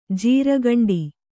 ♪ jīragaṇḍi